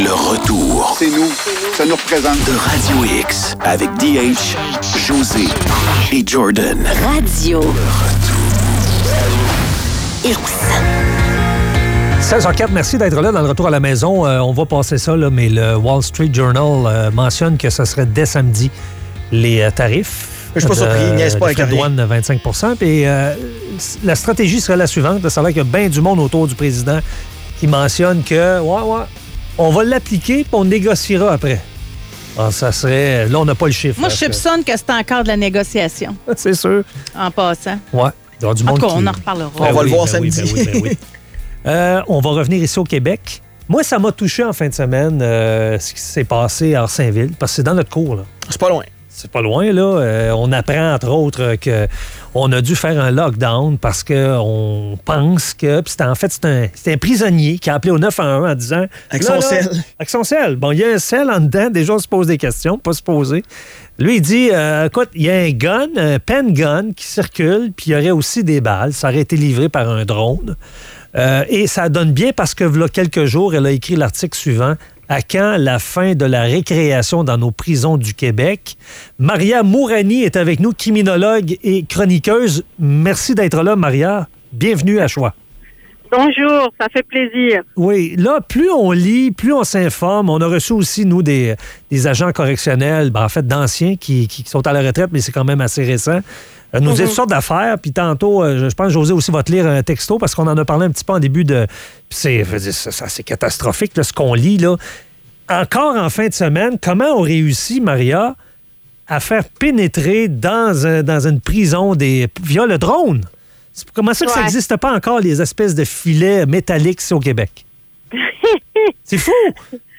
Entrevue avec Maria Mourani, criminologue.